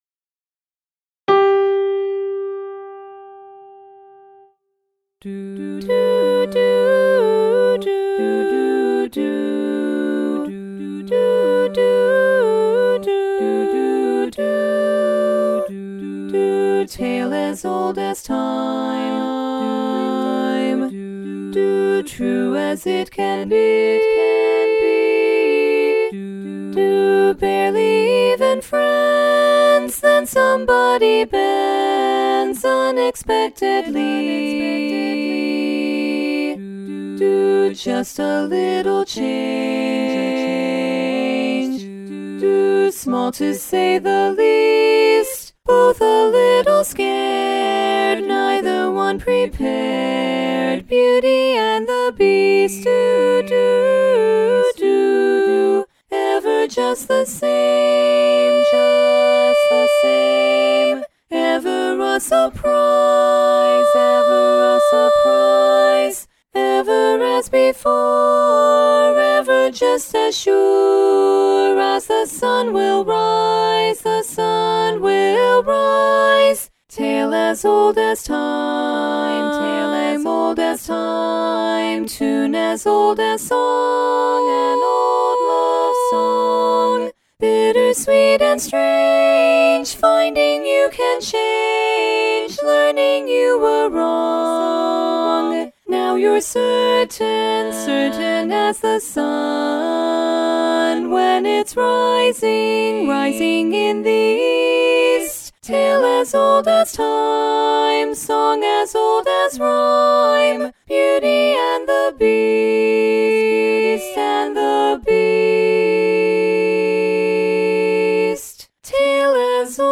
Lead